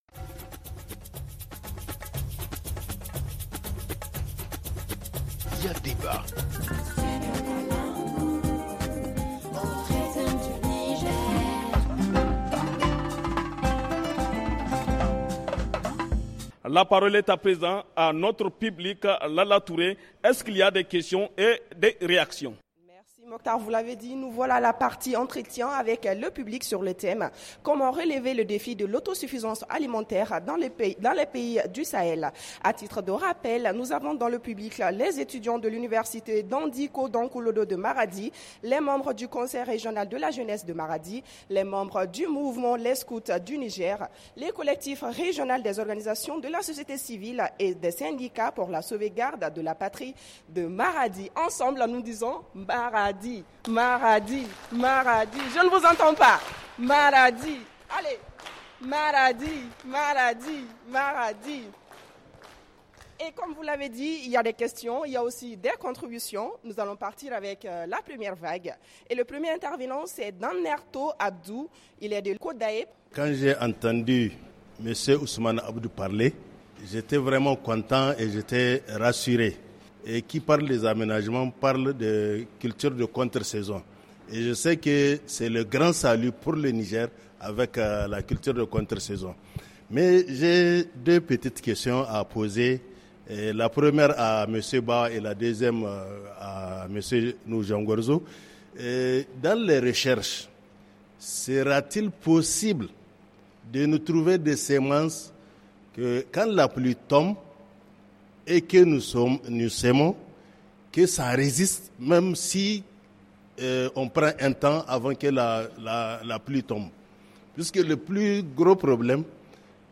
Avec nos invités, nous allons chercher à comprendre, à travers des analyses, la situation alimentaire et nutritionnelle dans les pays de l’Alliance des Etats du Sahel.